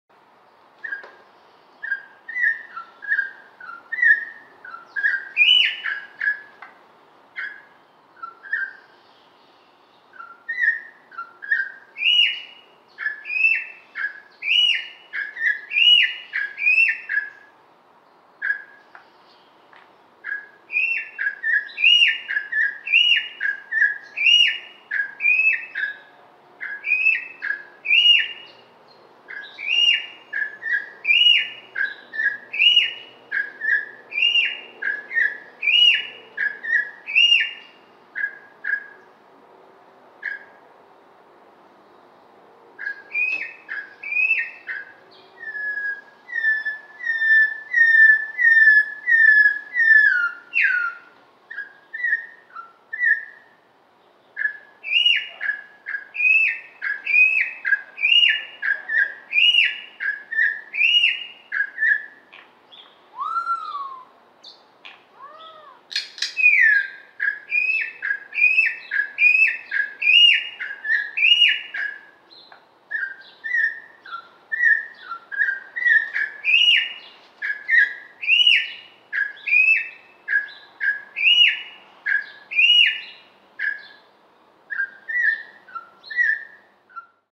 Corrupião Soltando o Canto (Sofrê)